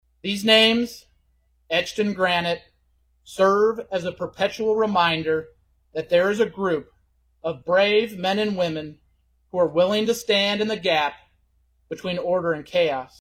(Radio Iowa) – The state held its annual ceremony honoring fallen law officers today (Friday) at the memorial near the State Capitol. Public Safety Commissioner Stephen Bayens says it's important to pause and remember.